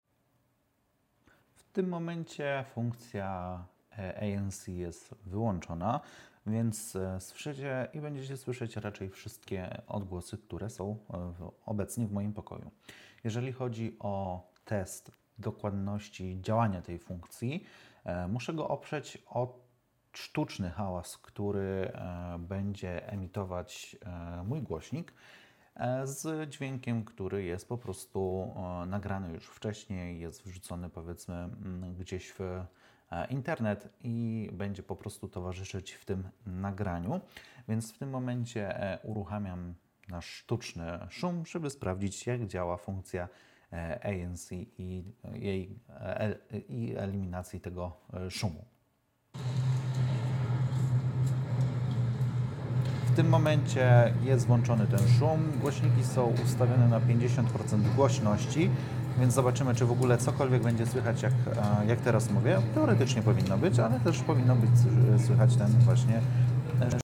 Próbka surowego dźwięku prosto z mikrofony – nagrana za pomocą iPhone 16 Pro Max:
HollyLand-Lark-A1-bez-ANC.mp3